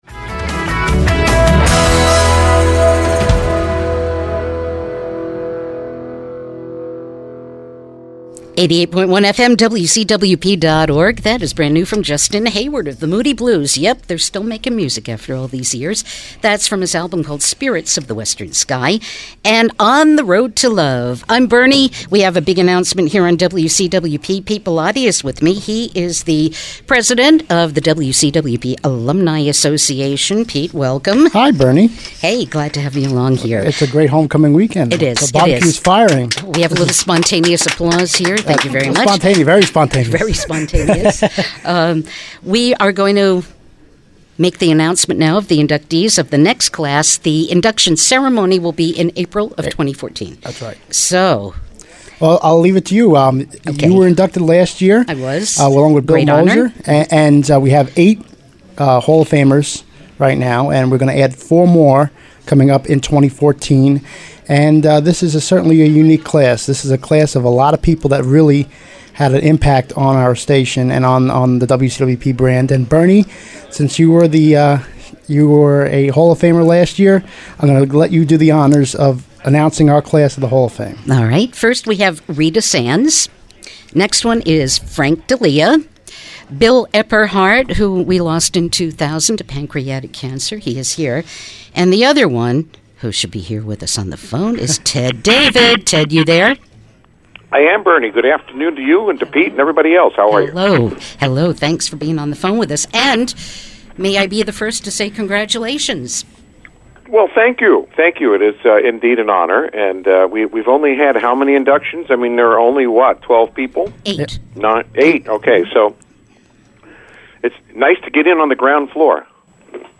I was there primarily for WCWP’s barbecue and announcement of 2014 inductees to their Hall of Fame.
2014-wcwp-hof-announcement-hard-limit.mp3